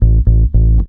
VOS BASS 1.wav